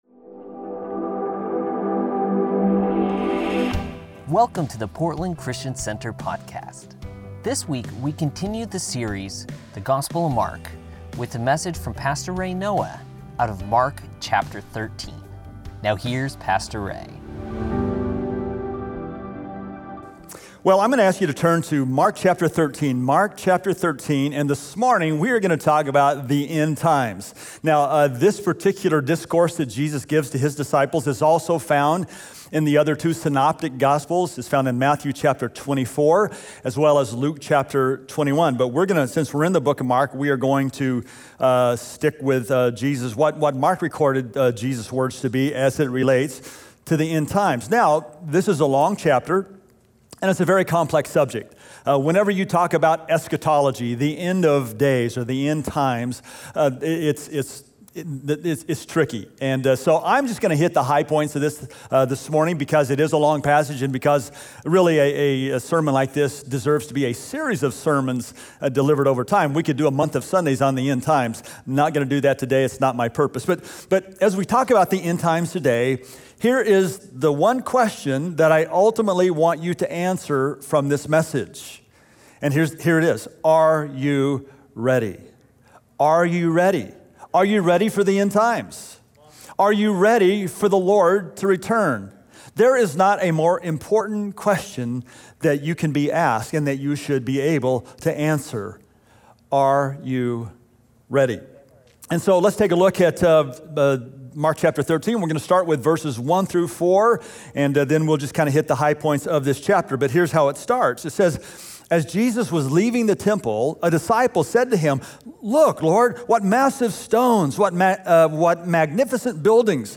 Sunday Messages from Portland Christian Center Mark 13 Nov 09 2020 | 00:37:59 1x 00:00 / 00:37:59 Subscribe Share Spotify RSS Feed Share Link Embed